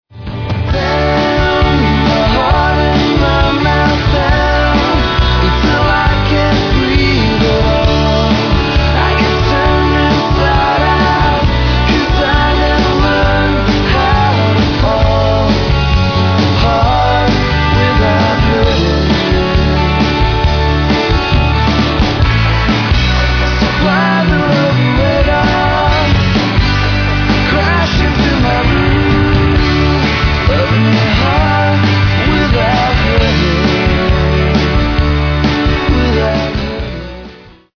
Vocals, Acoustic Guitar
Bass, Vocals, Accordion, Harmonium
Electric Guitar, Vocals, Pedal Steel
Drums, Vocals, Percussion, Tack Piano